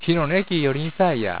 ためになる広島の方言辞典 な．